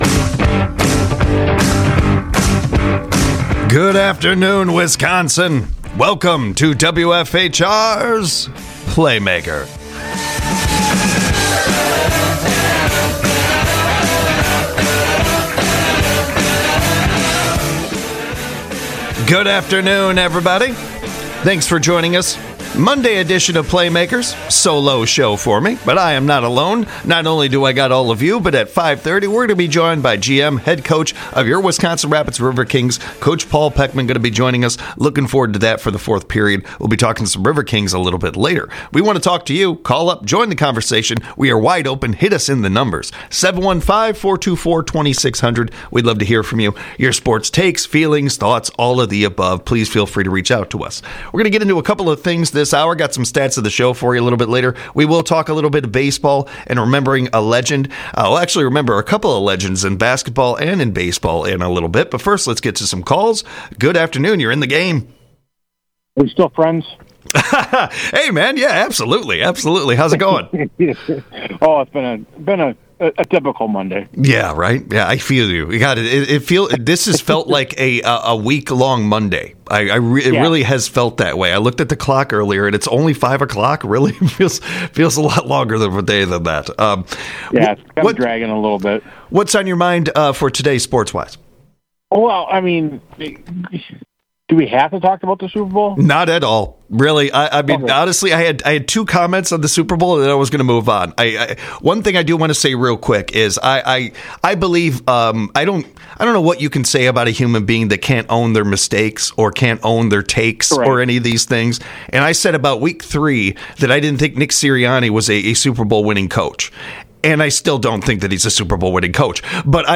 This show takes a deep dive into everything from local to world wide sports. With local sports guests and call-ins from the audience, this show is a highlight every Monday, Wednesday, Friday from 5pm - 6pm on WFHR.